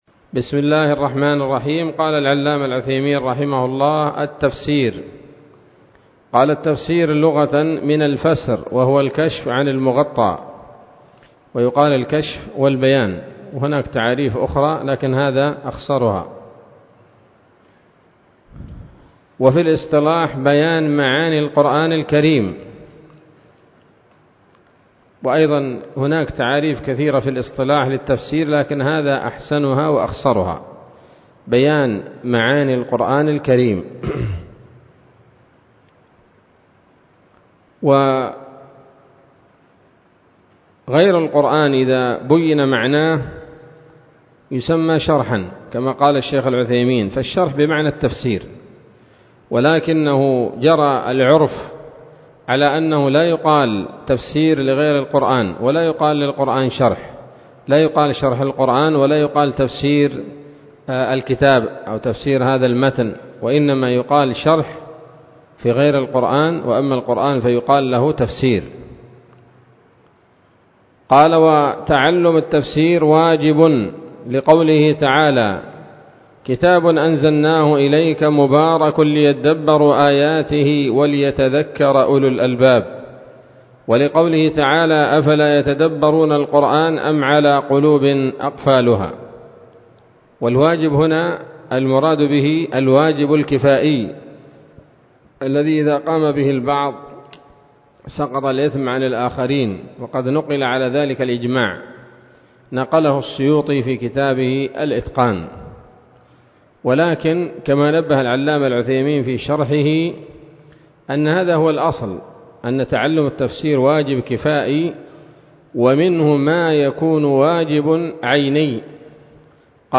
الدرس السابع عشر من أصول في التفسير للعلامة العثيمين رحمه الله تعالى 1446 هـ